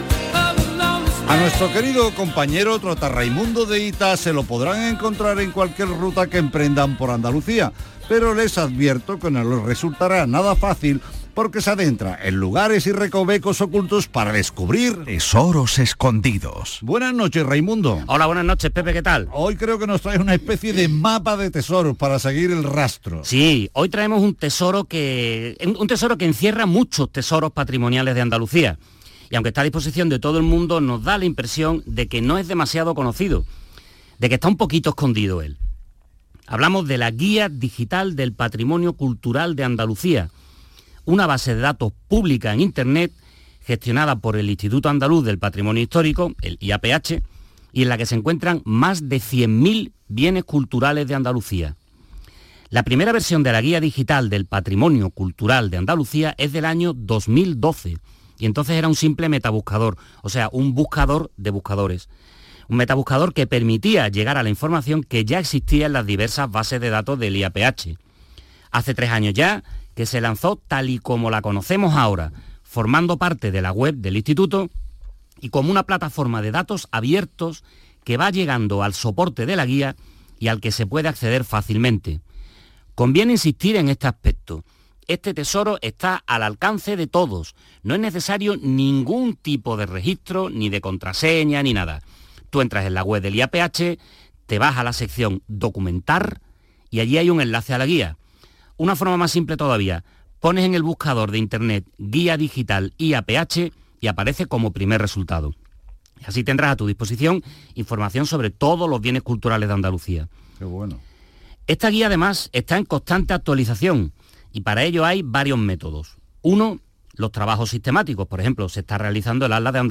Título : Reportaje radiofónico sobre la Guía digital del Patrimonio Cultural de Andalucía en el Programa "Patrimonio Andaluz"